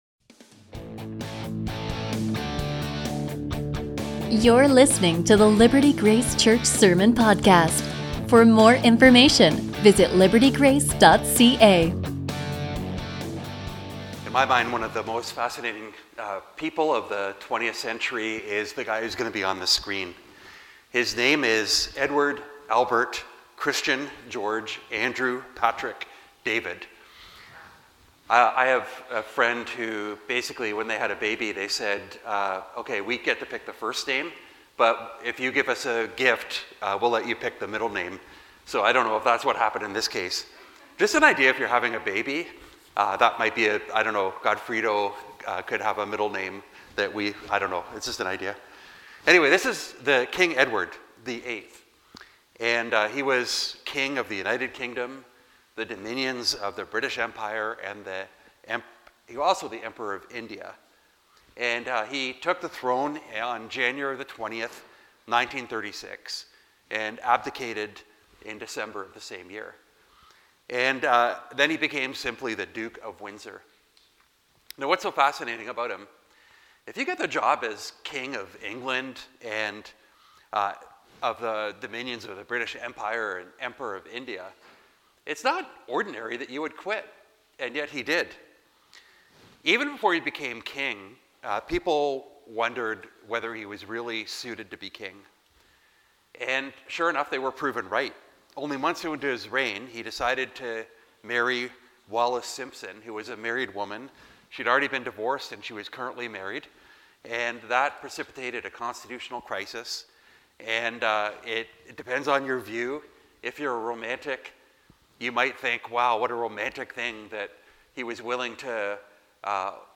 A sermon from Colossians 3:1-17